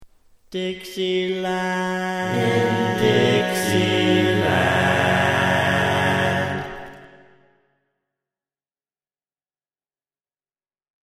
Key written in: F# Major
How many parts: 4
Type: Barbershop